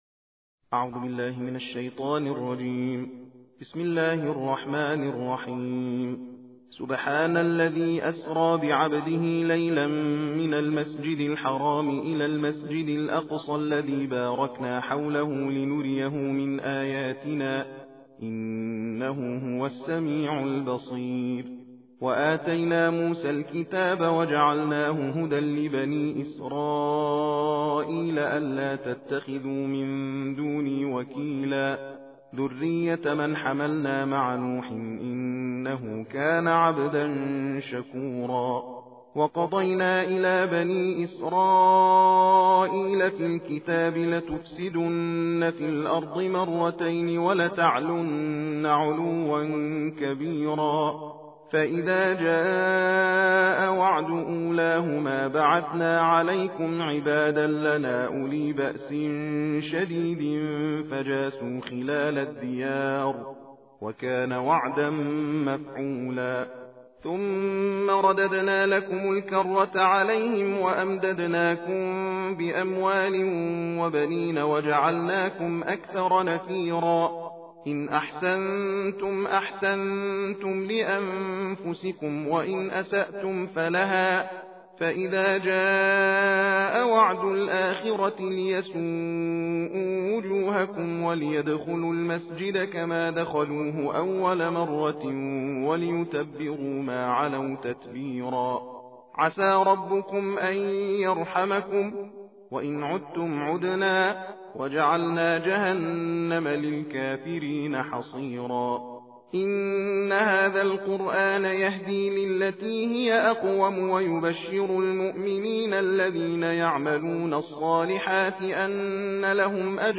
صوت/تلاوت جزءپانزدهم قرآن کریم